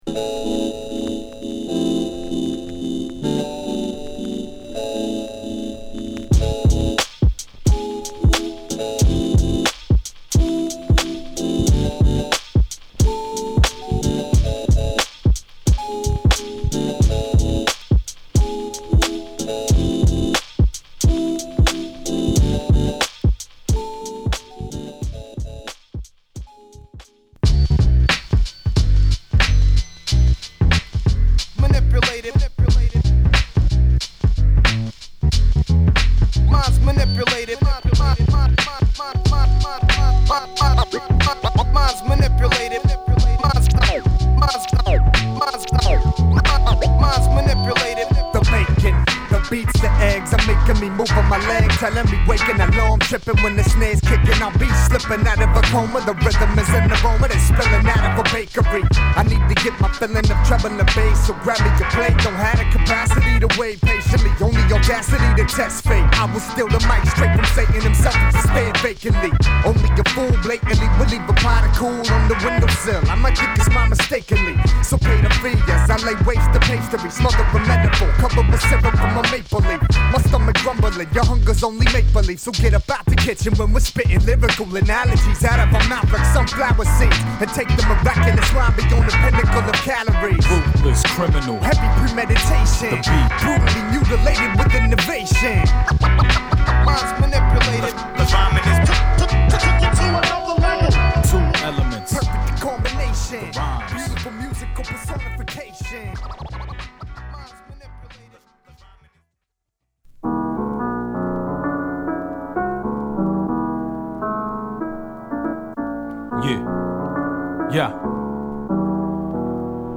ノースキャロライナはウィルミントン発の2MC